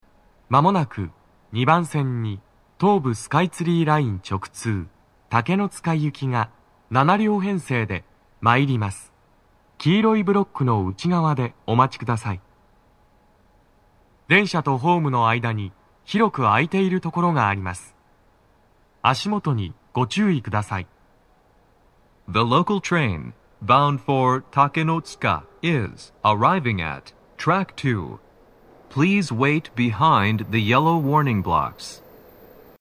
スピーカー種類 TOA天井型
鳴動は、やや遅めです。
男声